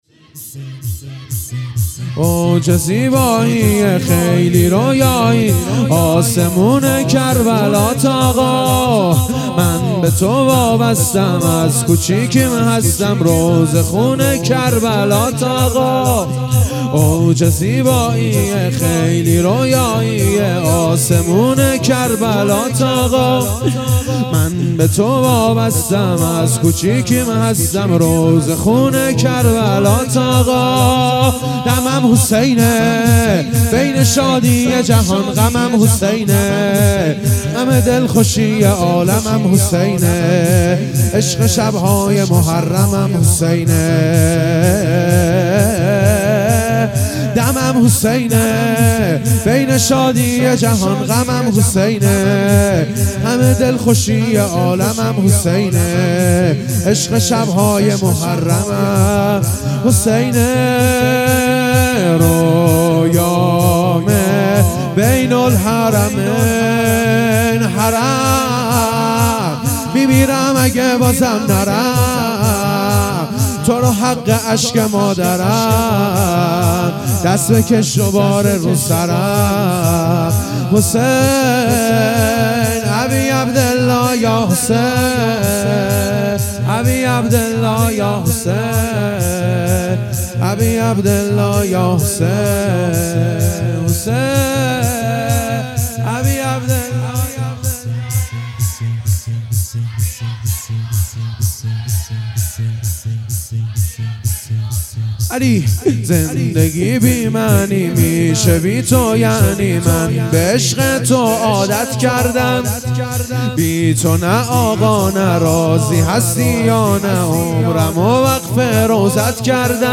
شور اوج زیبائیه خیلی رویائیه